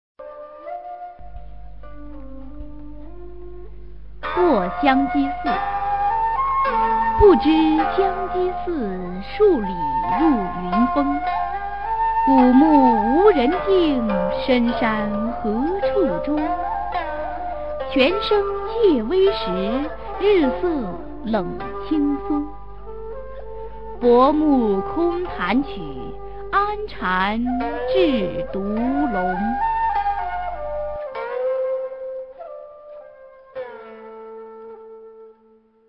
[隋唐诗词诵读]王维-过香积寺a 配乐诗朗诵